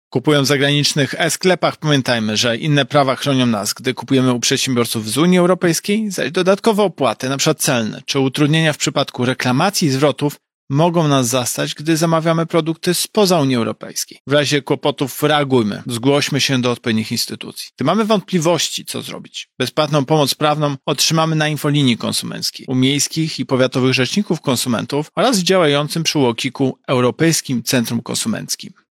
Prezes UOKiK przypomina, iż kupującym przysługują prawa z tytułu rękojmi bądź gwarancji towaru.